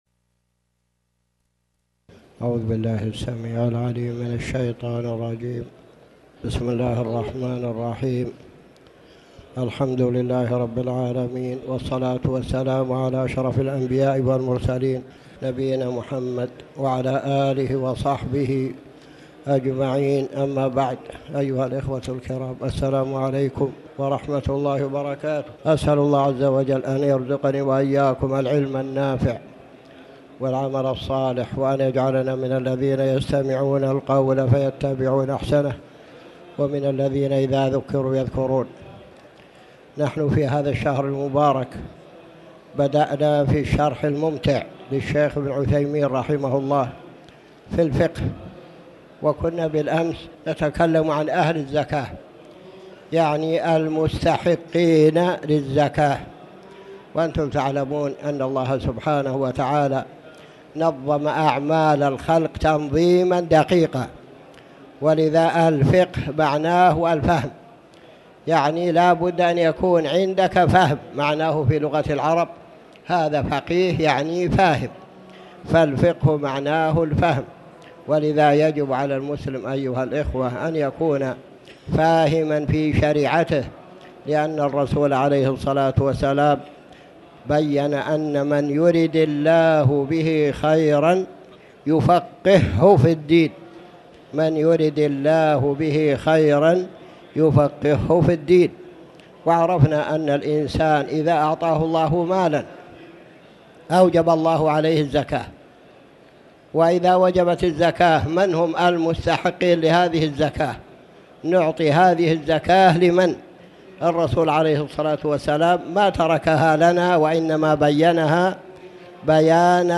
تاريخ النشر ٤ جمادى الأولى ١٤٣٩ هـ المكان: المسجد الحرام الشيخ